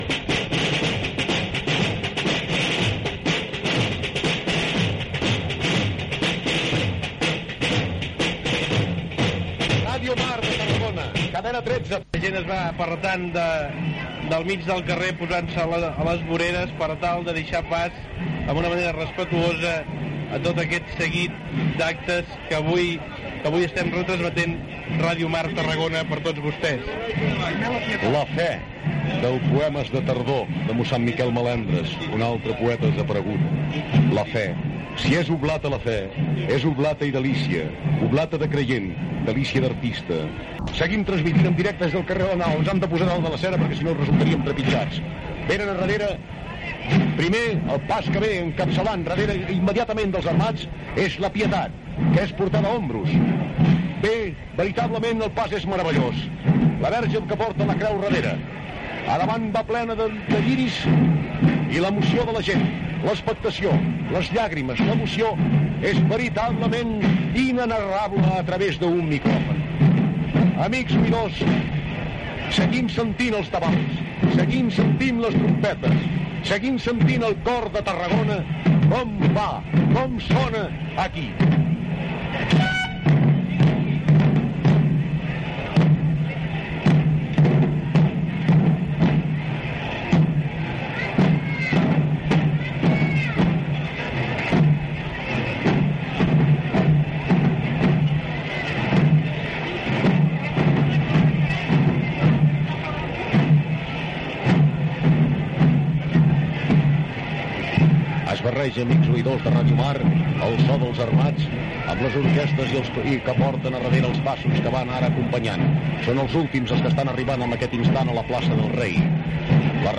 Identificació i transmissió de la recollida dels misteris de la processó de Setmana Santa de Tarragona